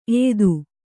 ♪ eydu